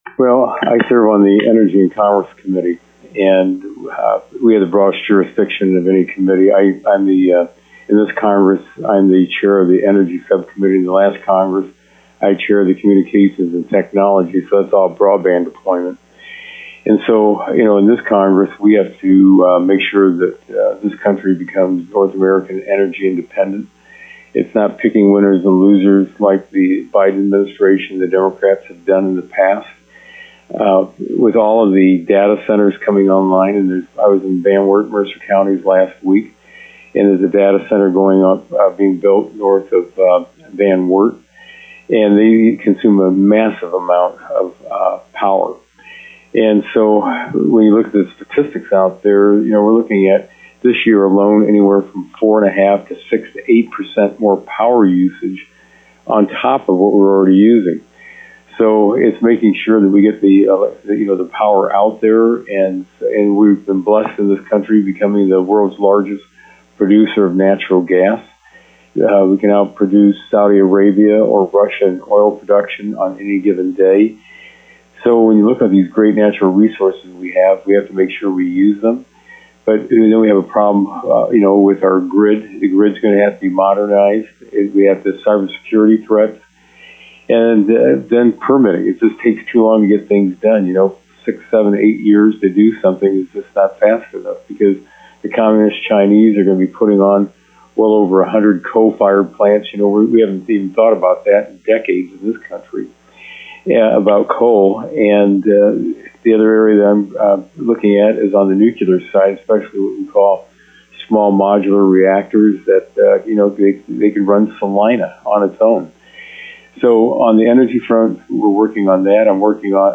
To hear comments with Congressman Latta: